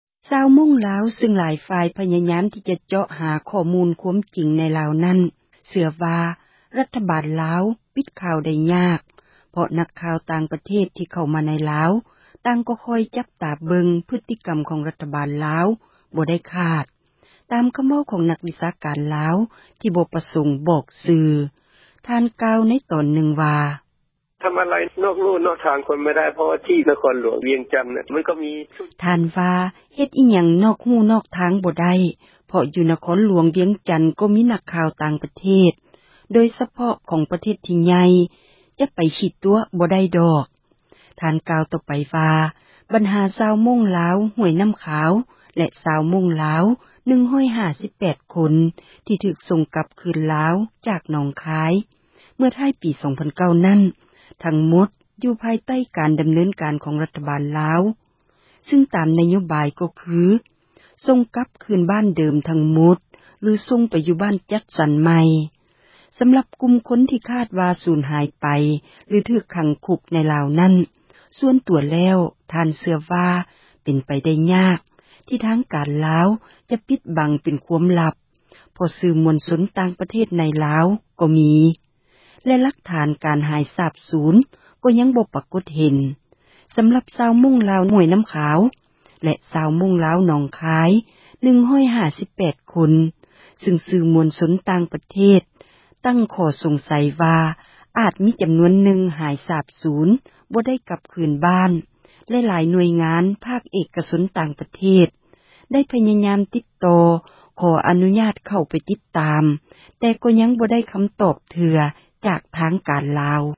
ທ່ານເວົ້າ ເປັນສໍານຽງໄທ ໃນຕອນນຶ່ງວ່າ: